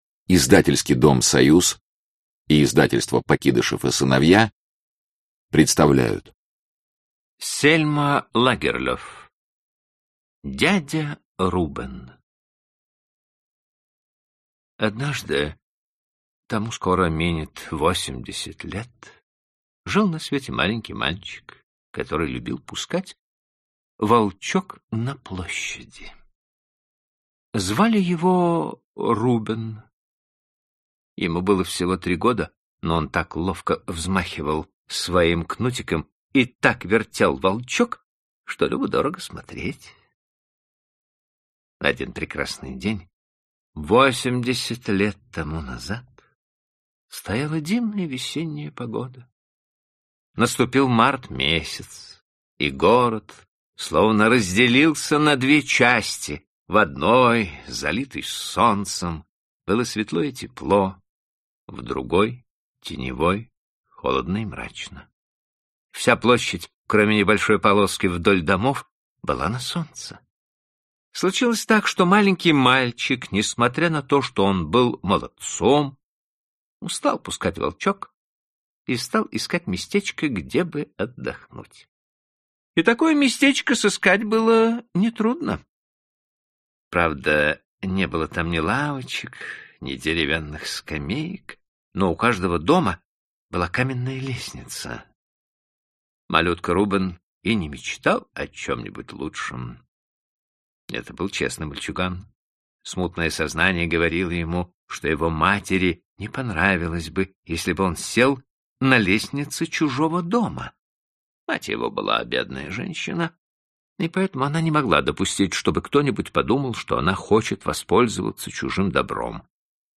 Аудиокнига Пушинка и другие рассказы | Библиотека аудиокниг